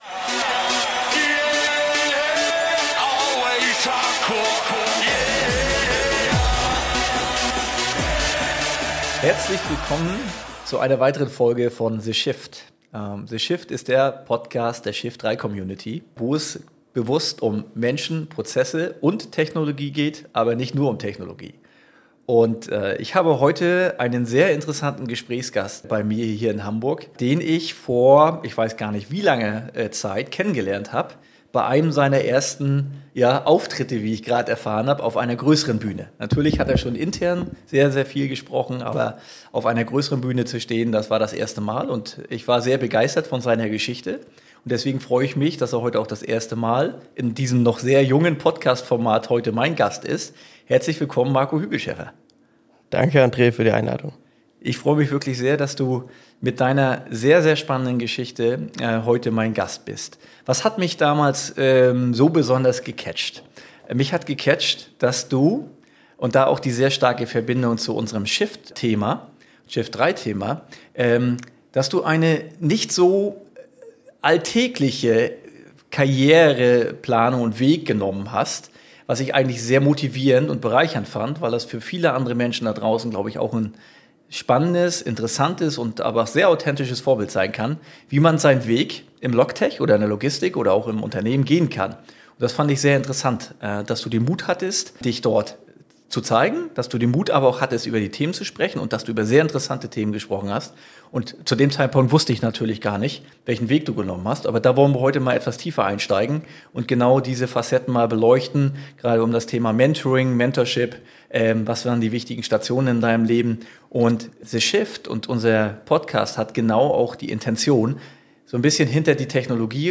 Ein Gespräch mit Tiefe und Bodenhaftung.